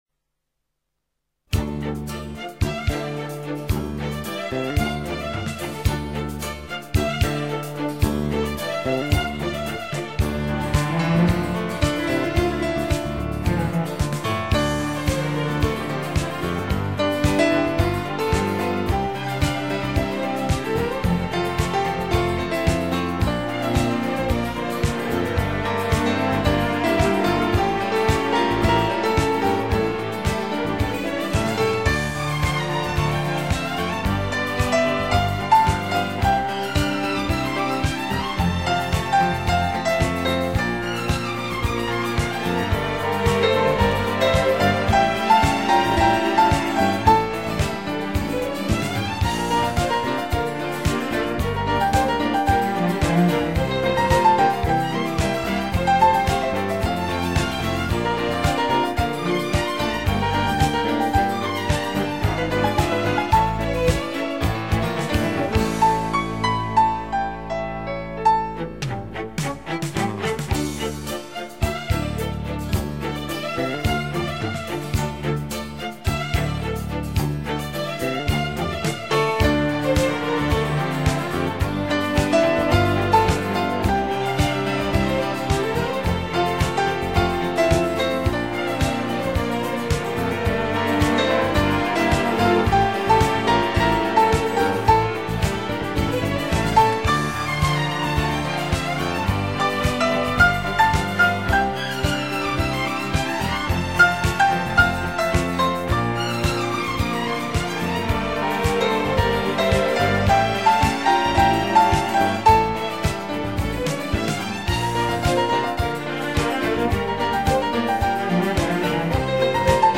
一柔一刚，刚柔相济！